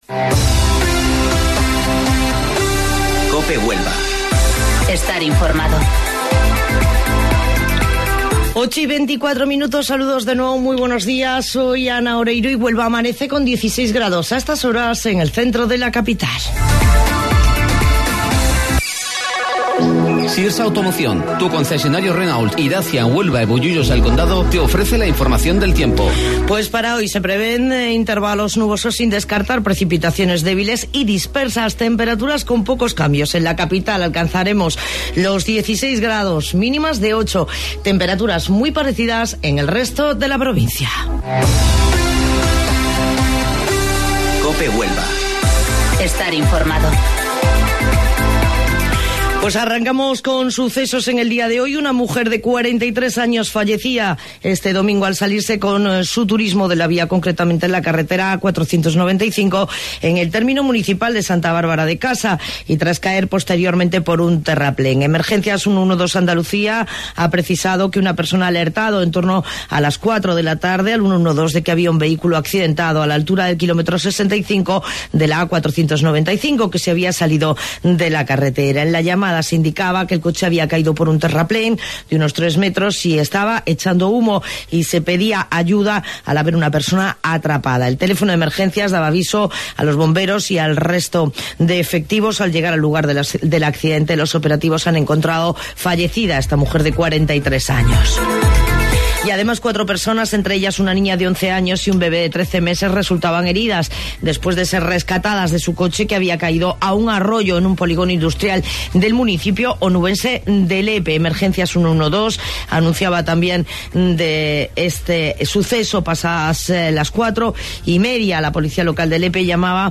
AUDIO: Informativo Local 08:25 del 2 de Diciembre